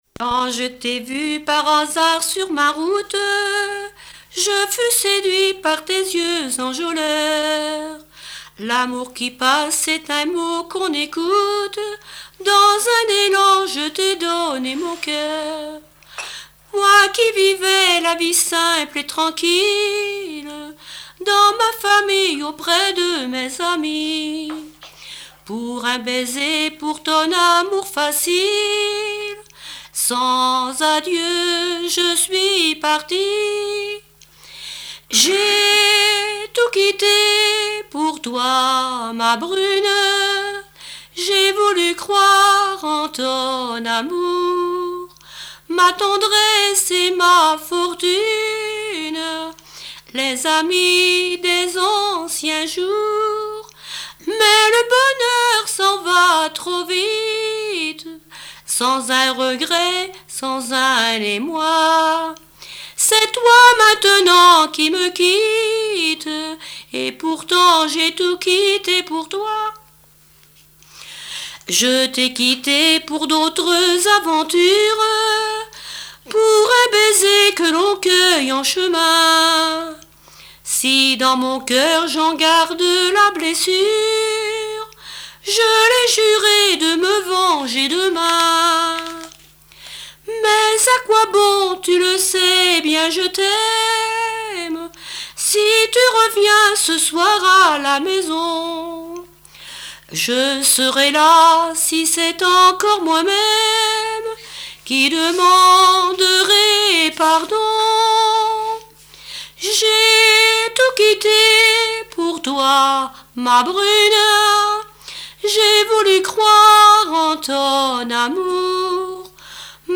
style chanson de variété
Genre strophique
Pièce musicale inédite